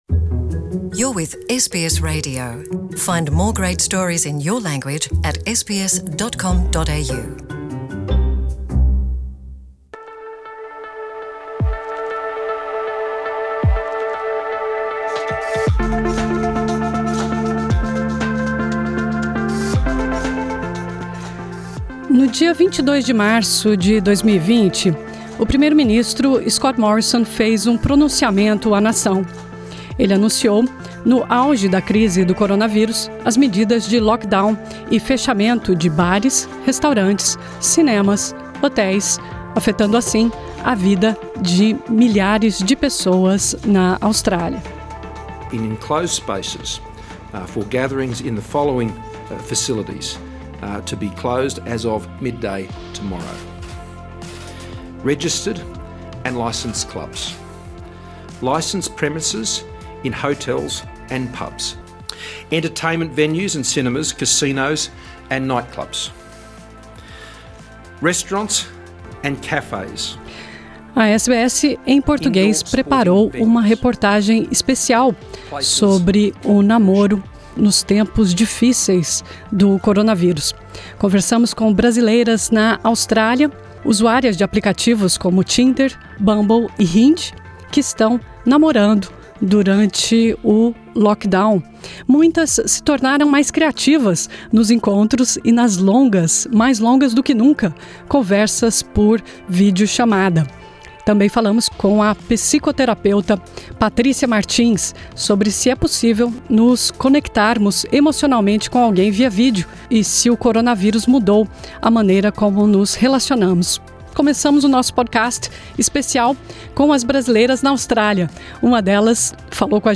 Conversamos com mulheres brasileiras em Brisbane, Sydney, Melbourne e no Brasil, que usaram de criatividade – e aplicativos de namoro – para encontrar a sua cara metade.